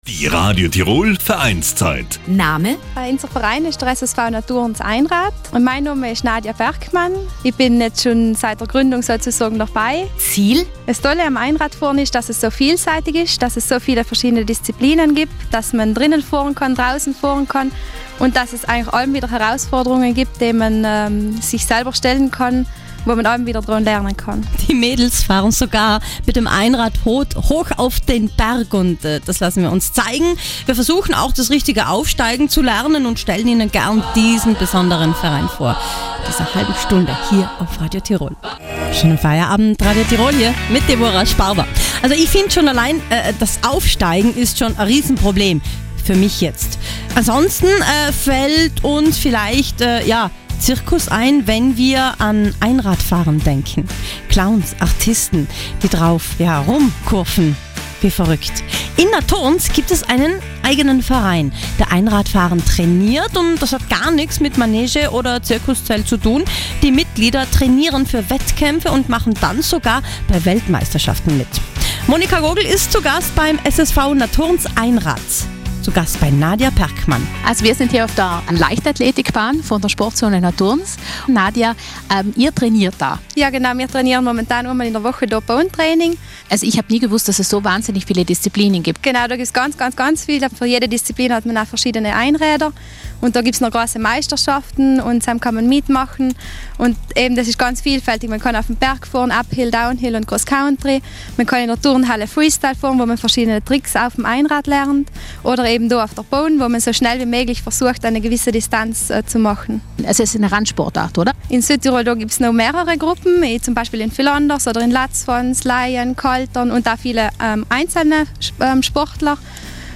Wir sind beim Training mit dabei.